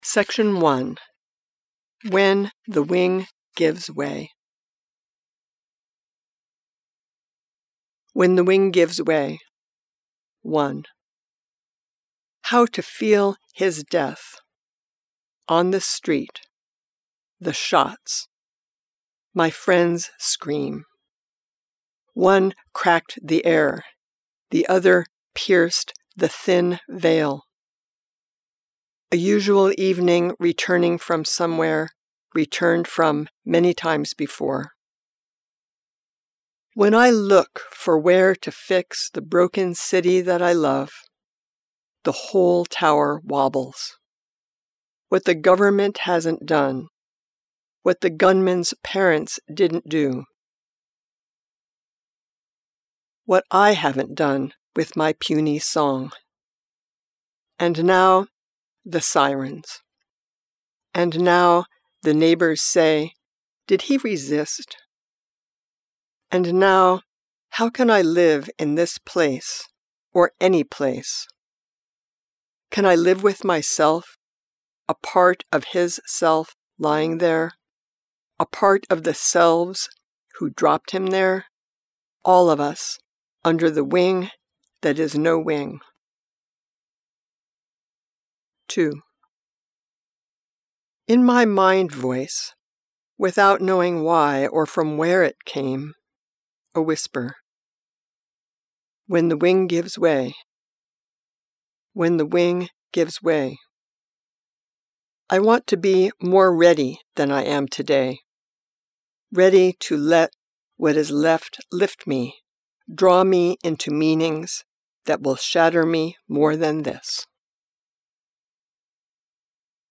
Audiobooks